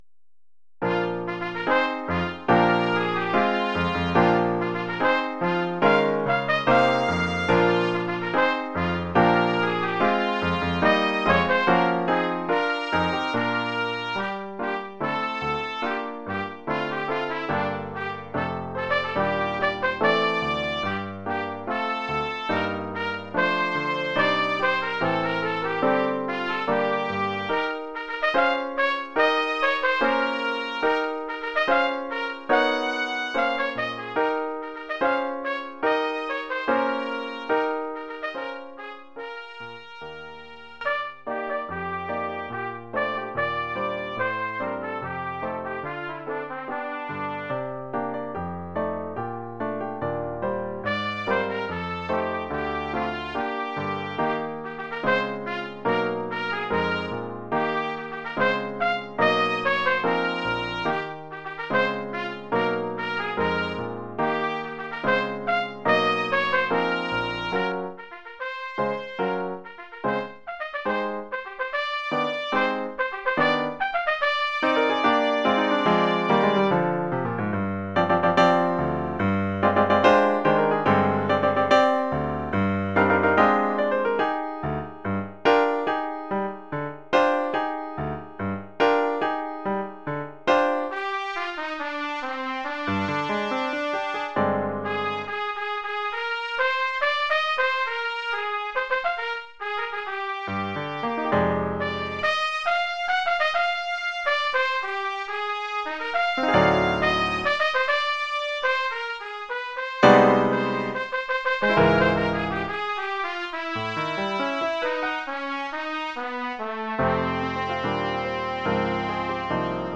Formule instrumentale : Trompette et piano
Oeuvre pour trompette ou
cornet ou bugle et piano.